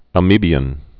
(ămə-bēən)